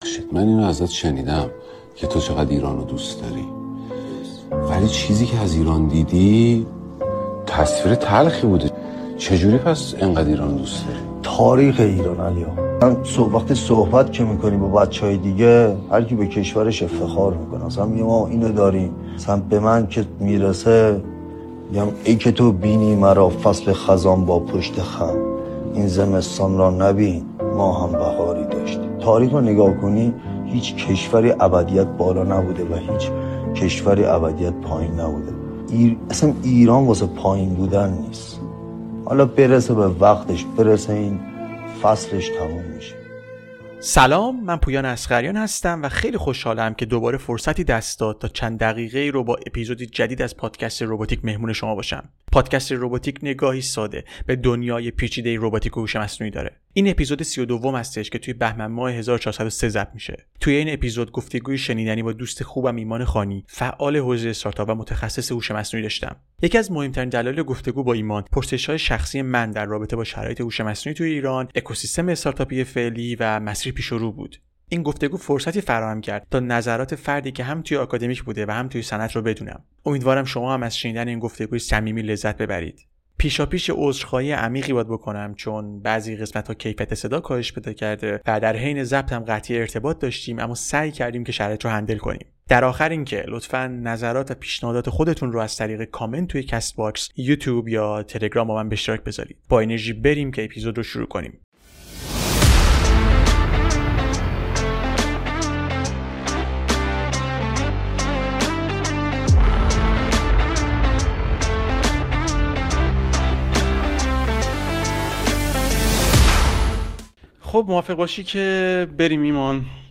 این اپیزود گفتگویی